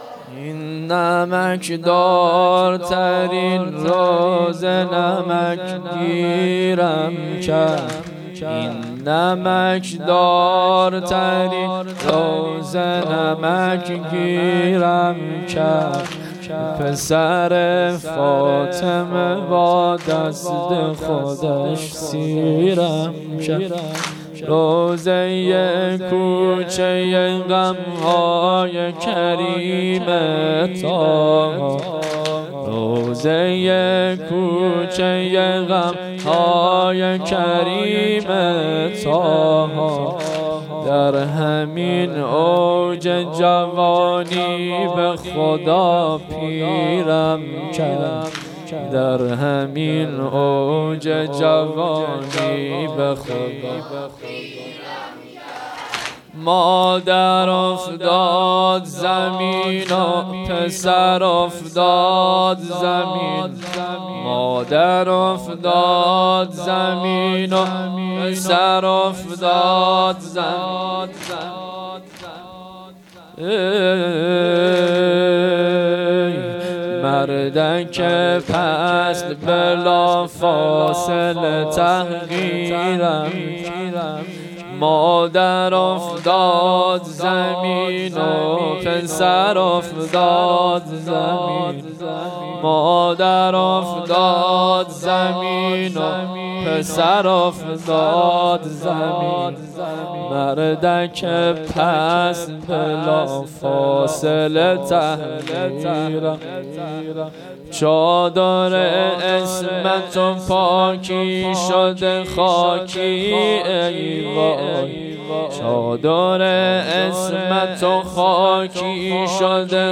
هیئت‌ هفتگی انصار سلاله النبی